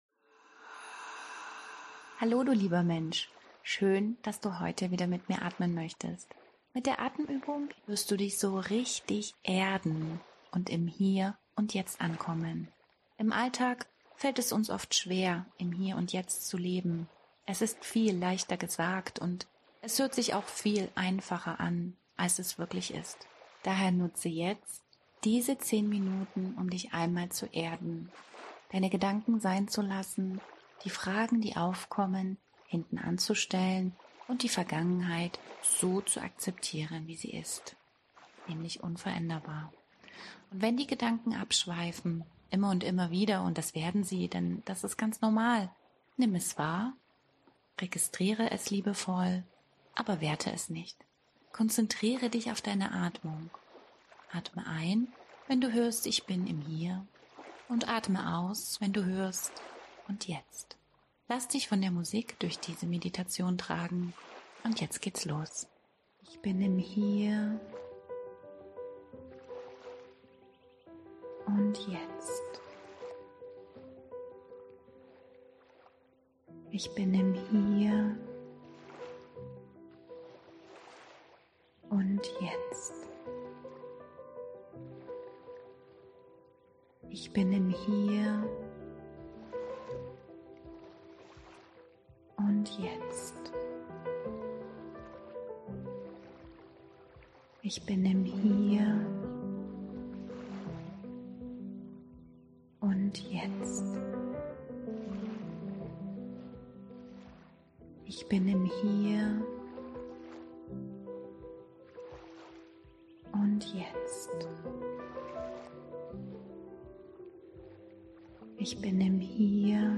Atemmeditation Hier&Jetzt